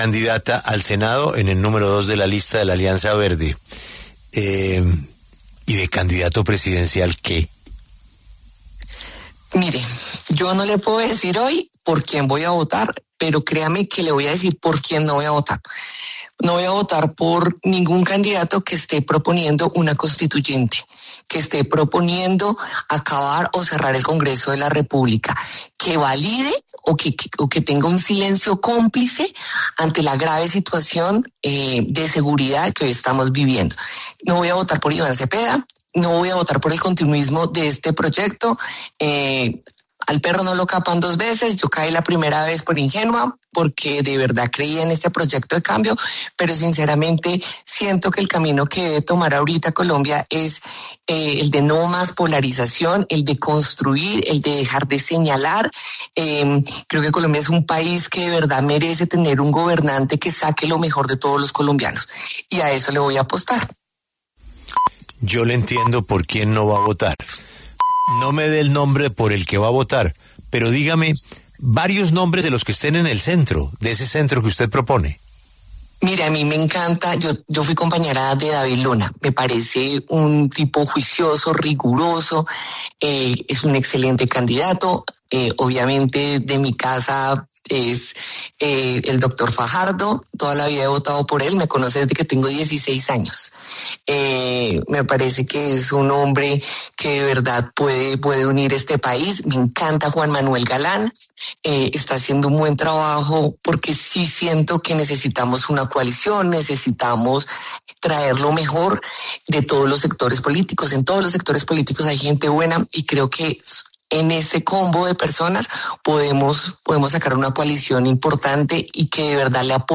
La representante del Partido Alianza Verde, Katherine Miranda, en diálogo en los micrófonos de La W, con Julio Sánchez Cristo, se refirió a las elecciones presidenciales de 2026 y reveló el candidato por el cual no votaría: Iván Cepeda, del Pacto Histórico.